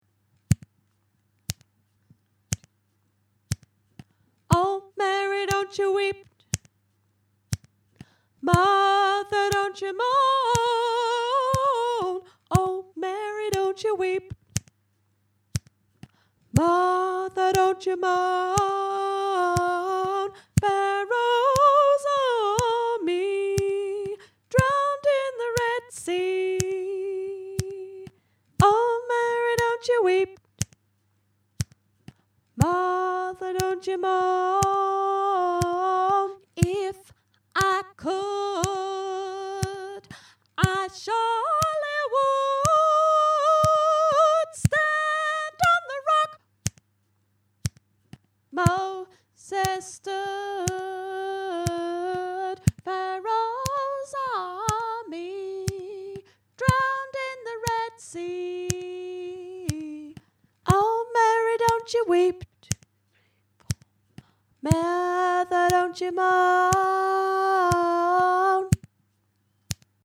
oh-mary-dont-you-weep-soprano.mp3